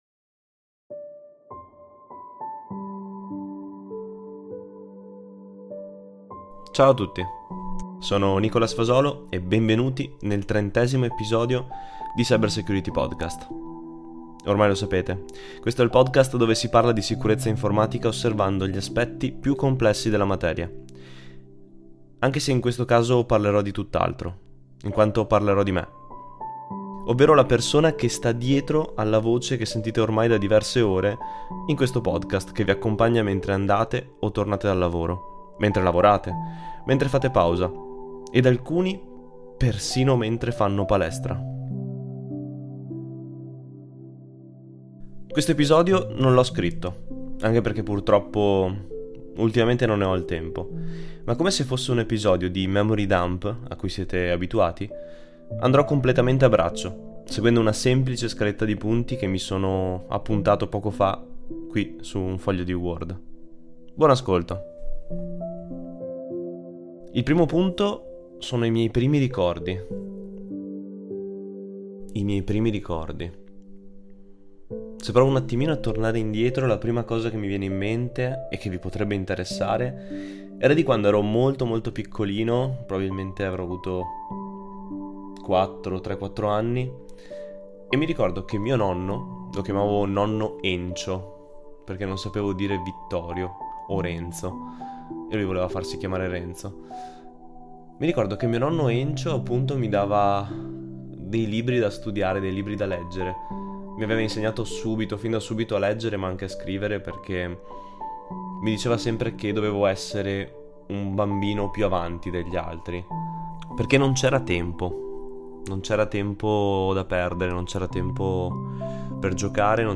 In questo episodio tutto registrato in "one take" condividerò con voi alcune memorie della mia vita, raccontandone un pò la storia.